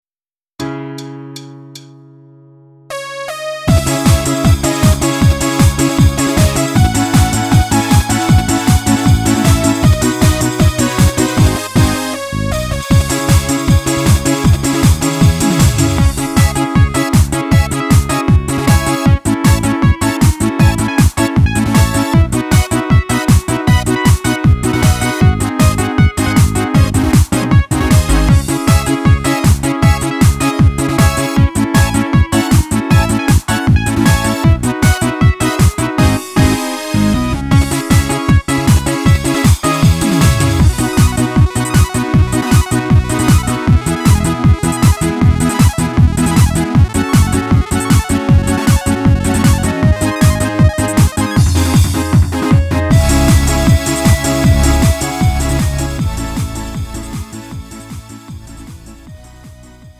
음정 -1키 3:37
장르 구분 Lite MR